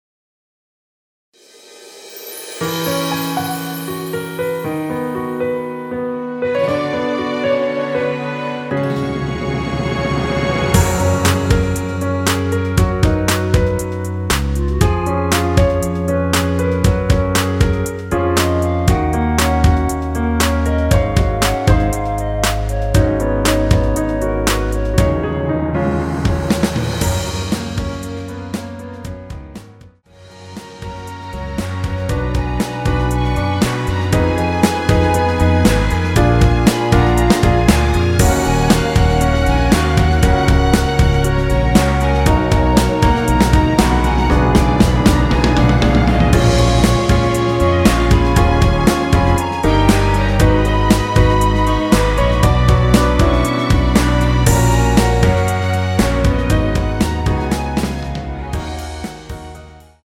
원키에서(-1)내린 멜로디 포함된 MR입니다.(미리듣기 확인)
Gb
앞부분30초, 뒷부분30초씩 편집해서 올려 드리고 있습니다.
중간에 음이 끈어지고 다시 나오는 이유는